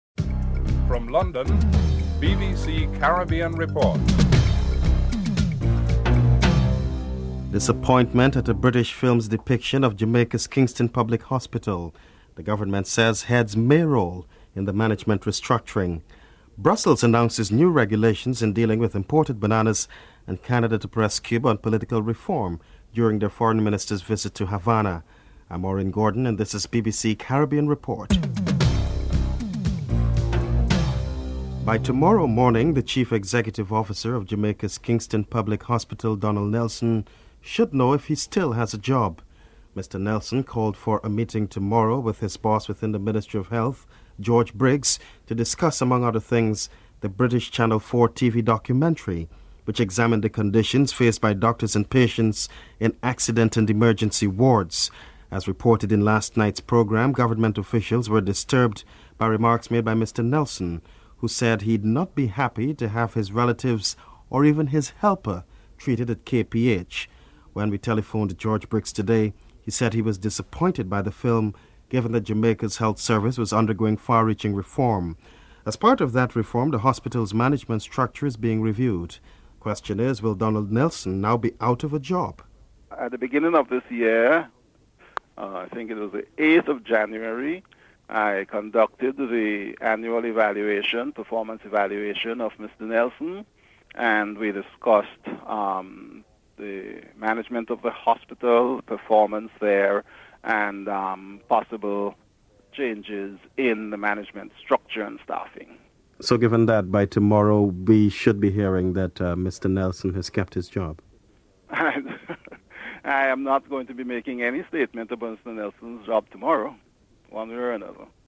1. Headlines (00:00-00:31)
4. Canadian Foreign Affairs Minister, Lloyd Axworthy arives in Cuba.
Interview with British lawyer (11:21-14:50)